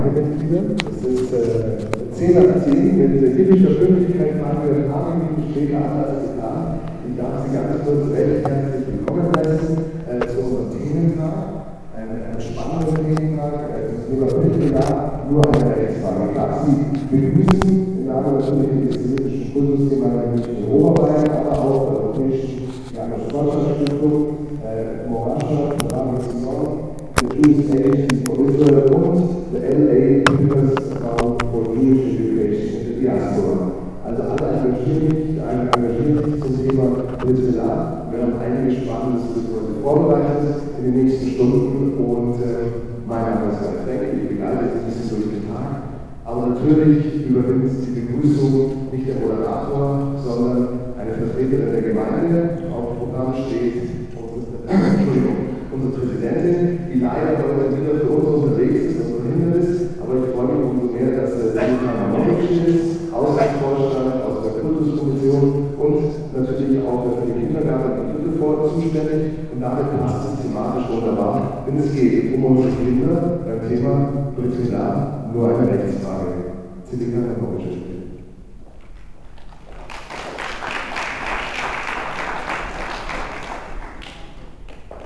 Dieser Vortrag wurde gegeben anläßlich des vom Rabbinat organisierten Lerntags zur Beschneidung, der am 23. Sep. 2012 in der Israelitischen Kultusgemeinde München statt fand.
Begrüßung